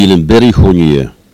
Locution